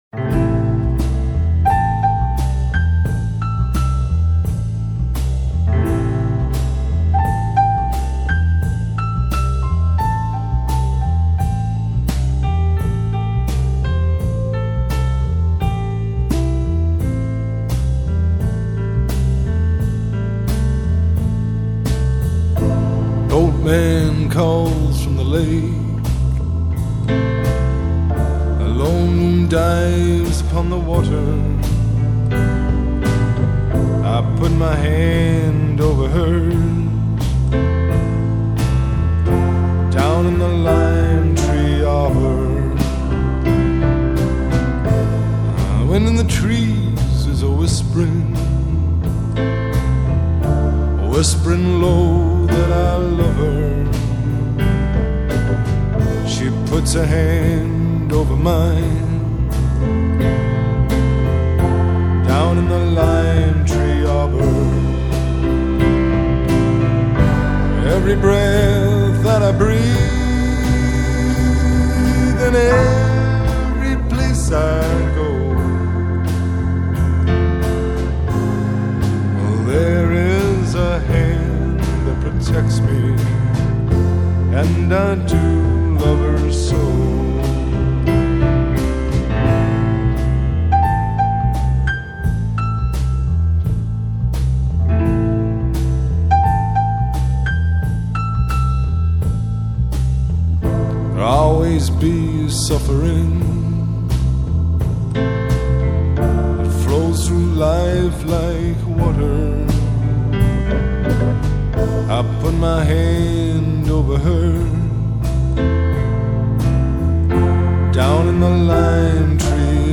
Alternative Rock, Blues Rock